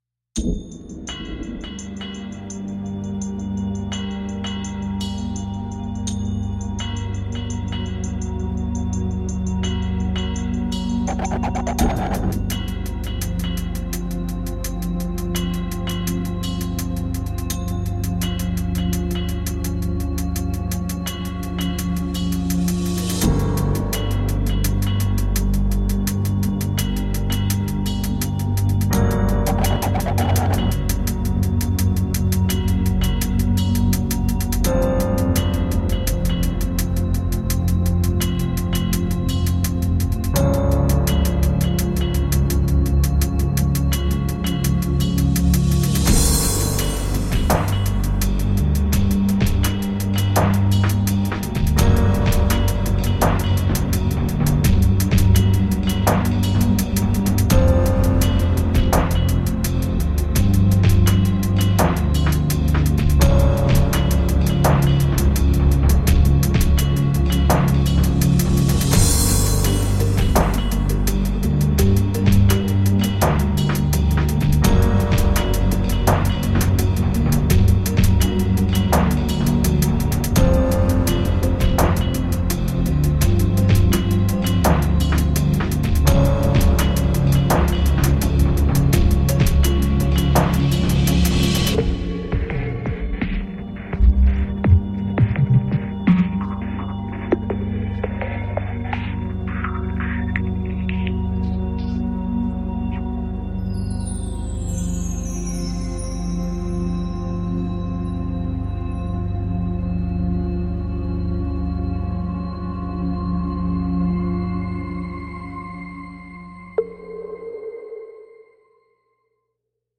Atmospheric, instrumental soundtracks for daydreaming.